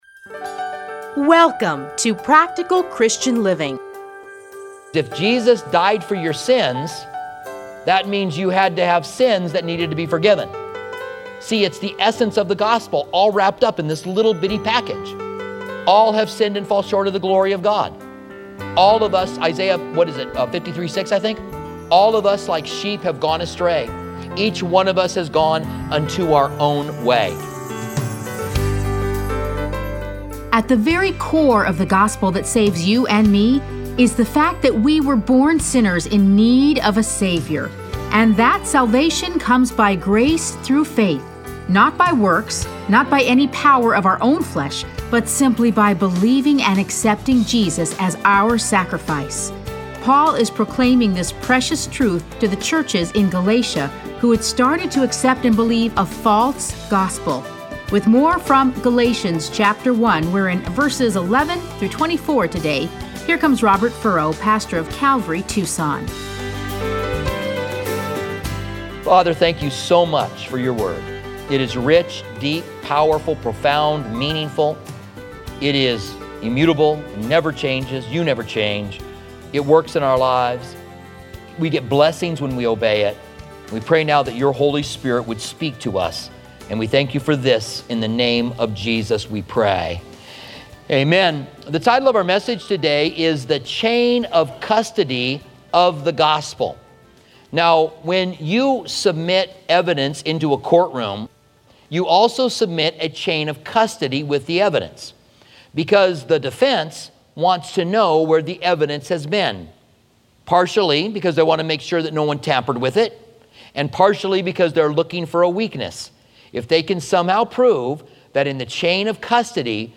Listen to a teaching from Galatians 1:11-24.